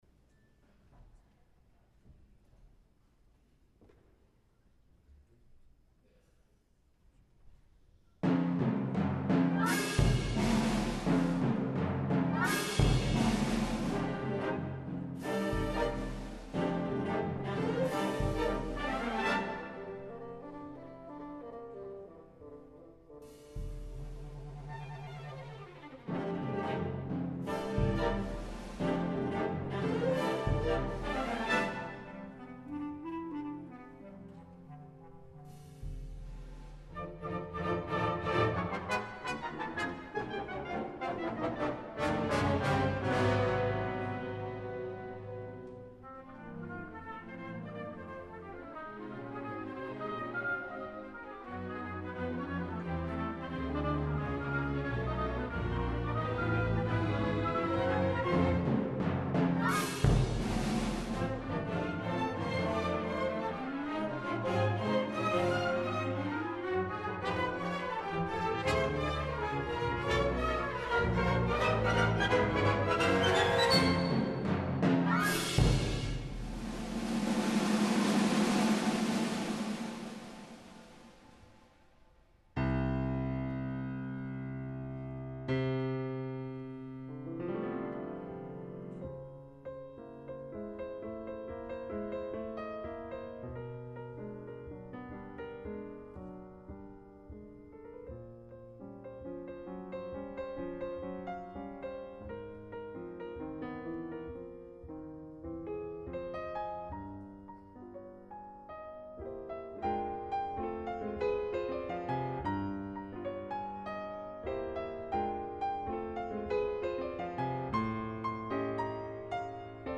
GEORGE GERSHWIN: CONCERT EN FA amb la Filarmonica della Scala, Stefano Bollani i Riccardo Chailly (vídeo)
per a piano i orquestra